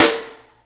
High Snare OS 01.wav